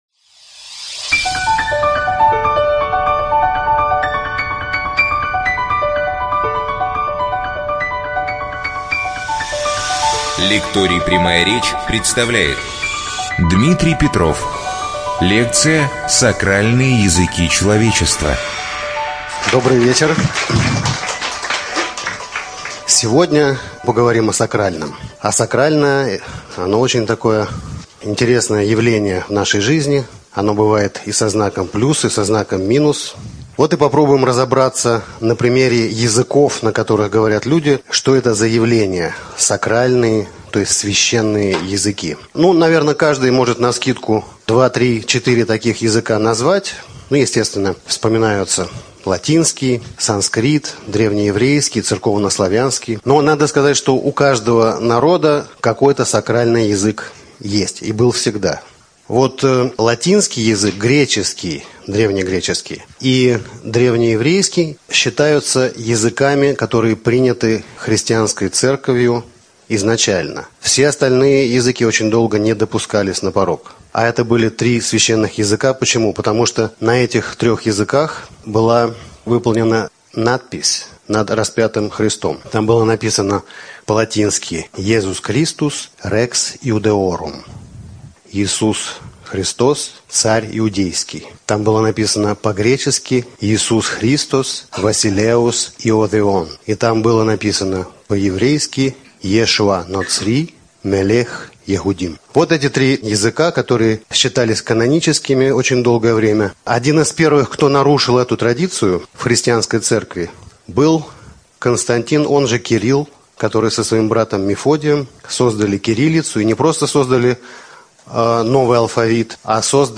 НазваниеСакральные языки человечества. Лекция
ЧитаетАвтор